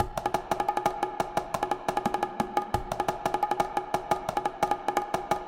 拉丁打击乐器邦戈斯8
描述：一套新的涉及拉丁打击乐循环的作品。更确切地说，是邦戈鼓和康加鼓。
标签： 175 bpm Ethnic Loops Percussion Loops 1.00 MB wav Key : Unknown
声道立体声